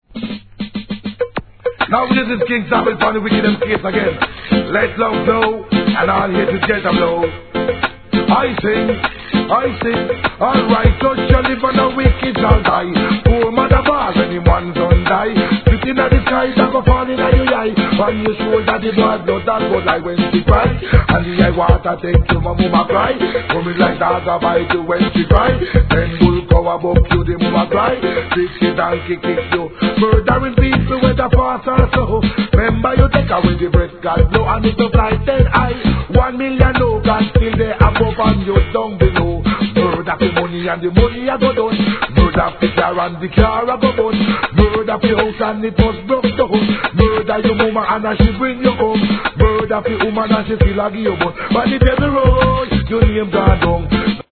REGGAE
BUJU BANTON似のVOICEで好い感じです!